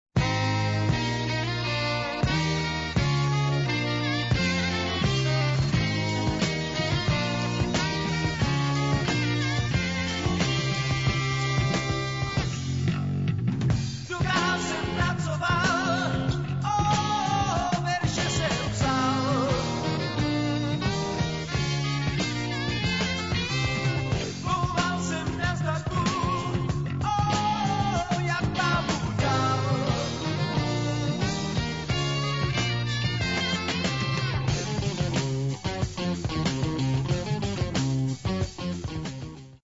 a legend of Czech rock'n'roll history.